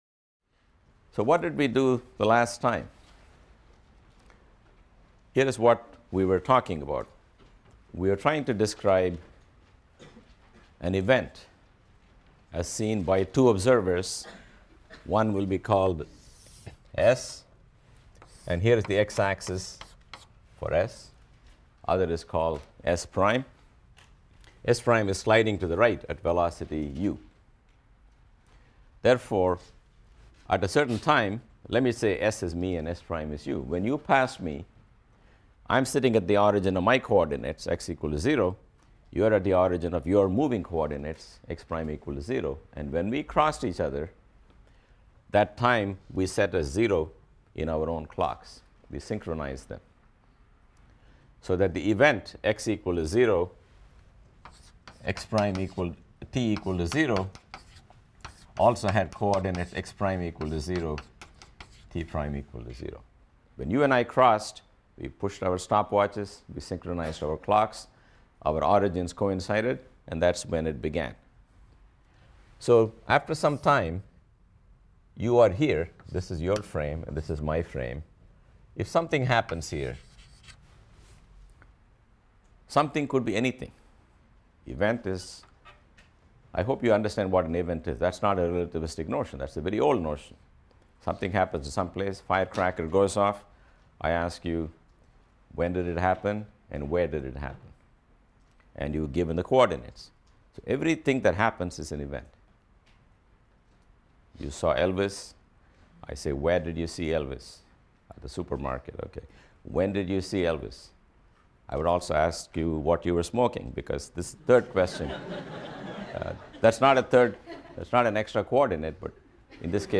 PHYS 200 - Lecture 13 - Lorentz Transformation | Open Yale Courses